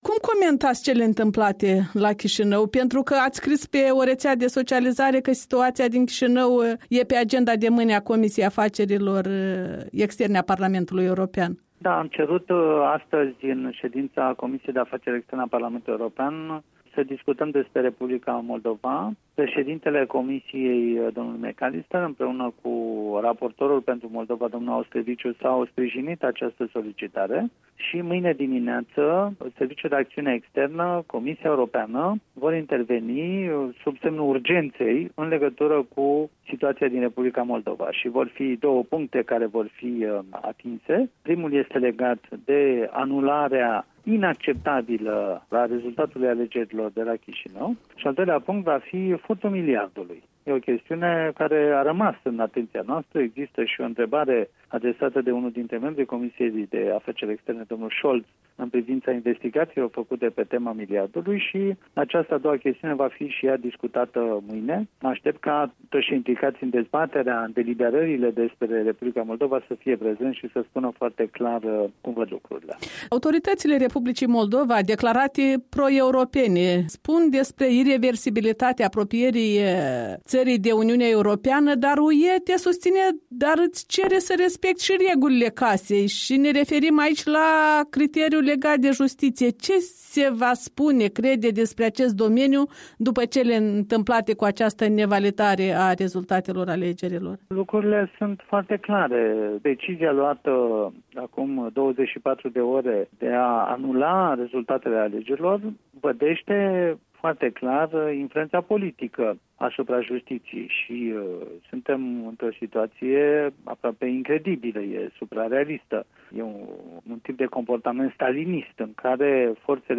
Un interviu cu europarlamentarul român, coordonator al Grupului Partidului Popular European (PPE) din PE.
Interviu cu europarlamentarul român Cristian Preda